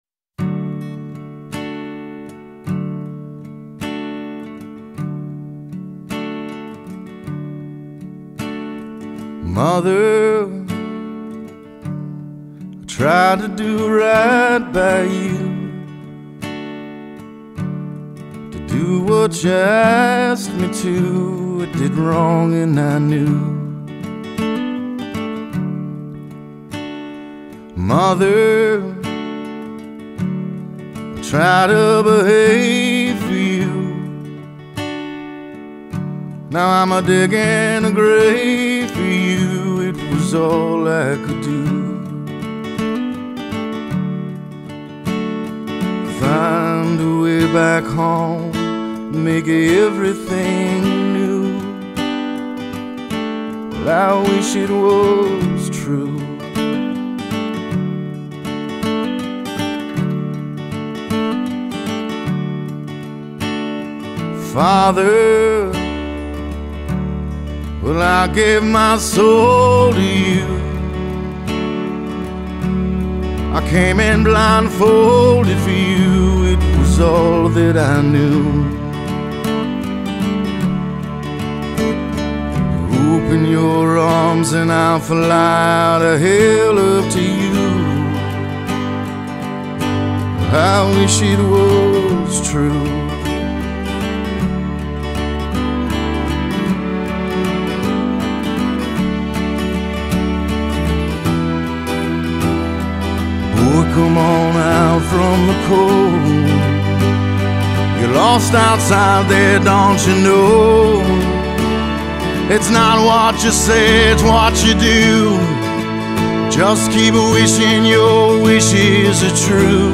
country Rock موزیک کانتری